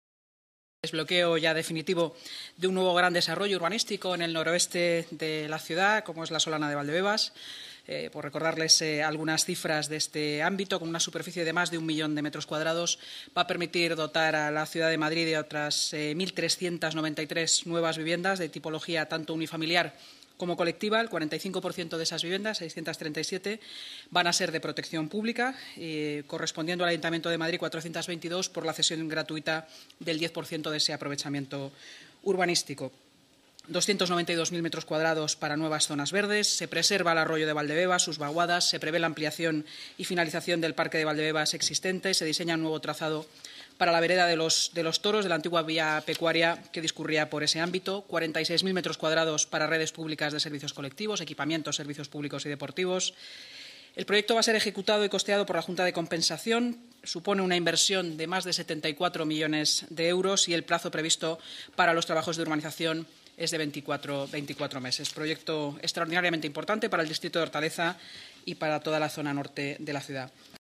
Nueva ventana:Inma Sanz, portavoz municipal